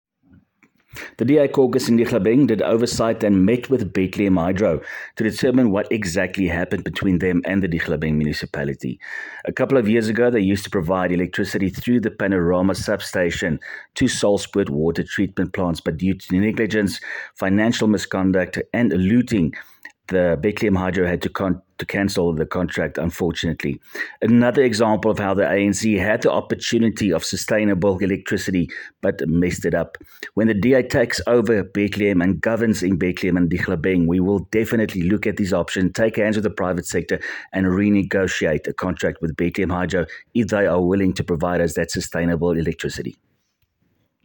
Afrikaans soundbites by Cllr Willie Theunissen.